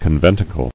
[con·ven·ti·cle]